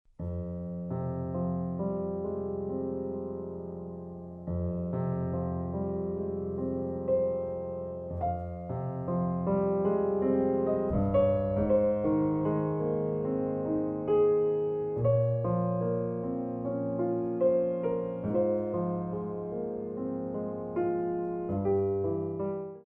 Adage